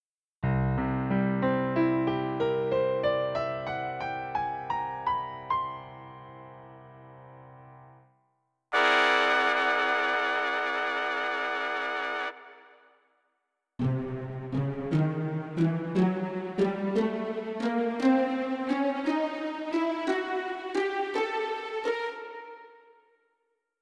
Натуральный звукоряд, целотонный аккорд, гамма тон-полутон.